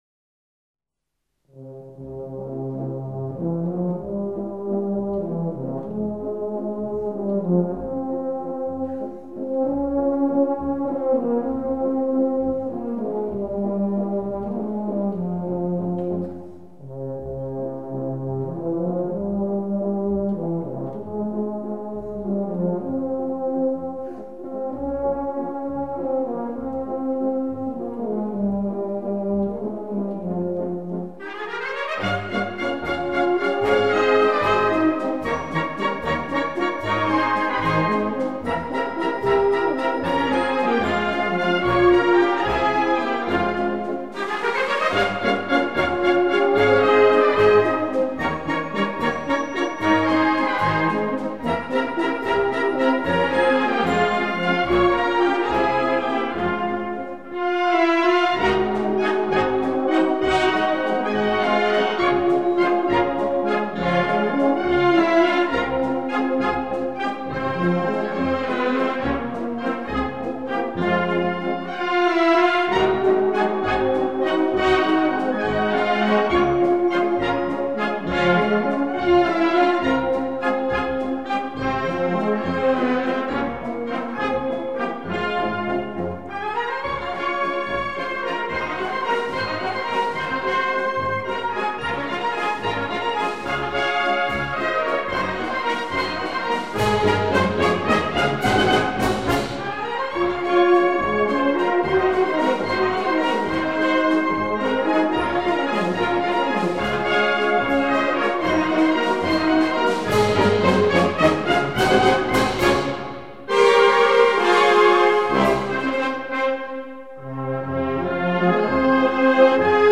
Предлагаю небольшой вечерний концерт старых вальсов в исполнении духовых оркестров.